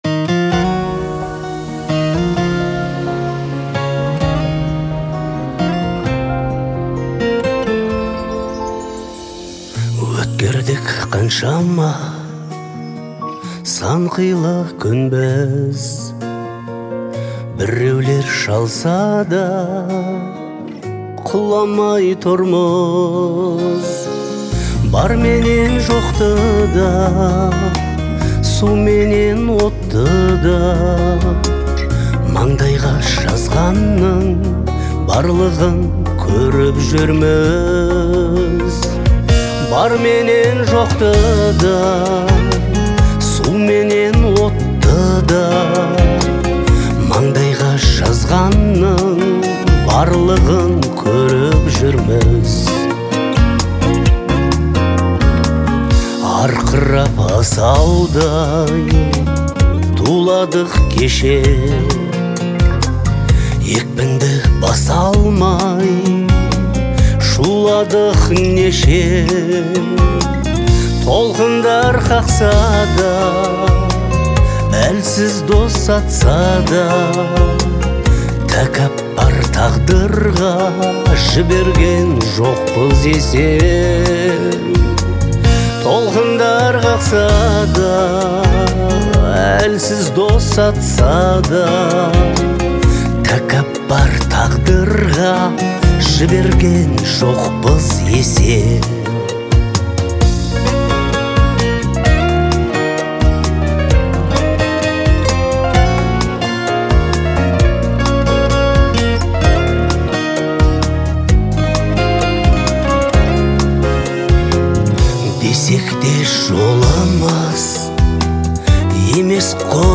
его голос наполняет мелодию теплом.